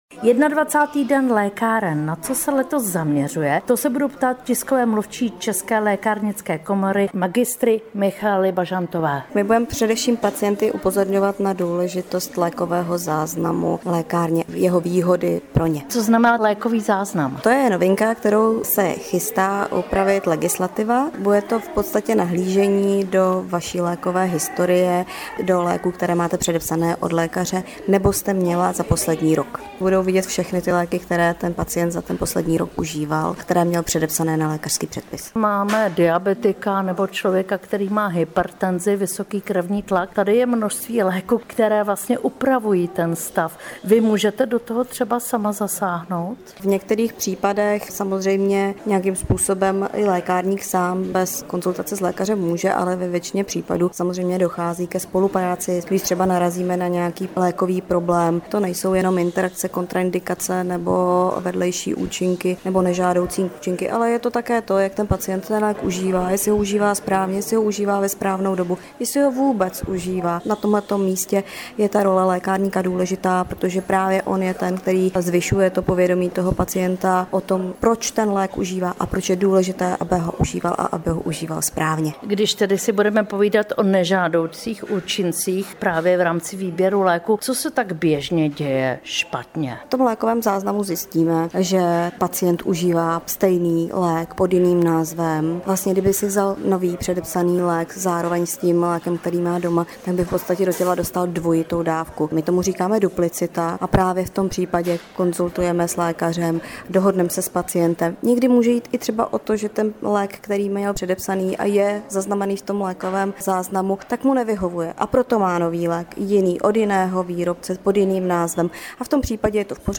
Audio rozhovor